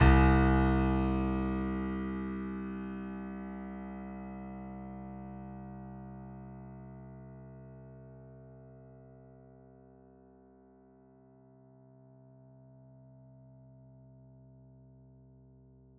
ピアノの音ピアノのスペクトログラム:アコースティック楽器ならではの複雑な時間的変化を辿る。周波数の分布がまばらで、変化も音が減衰して戻ったりのような波がある。
P-ENV-spectrogram-piano.mp3